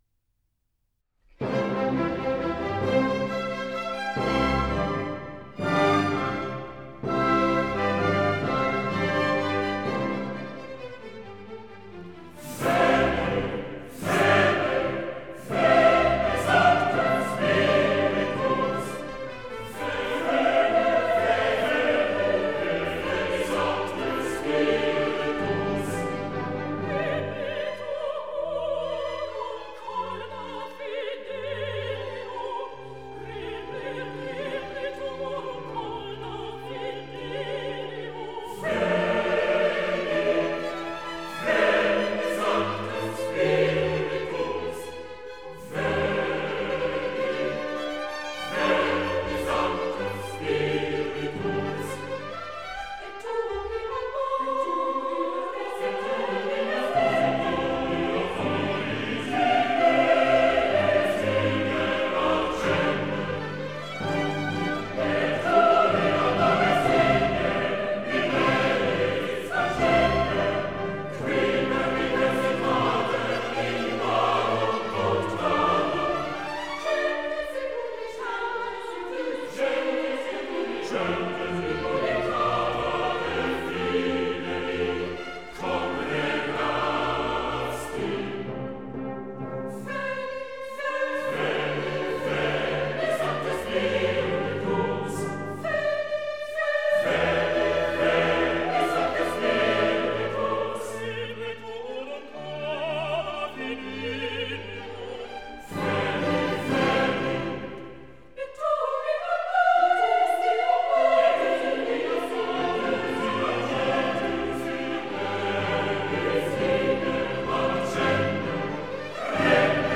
Antiphon
in C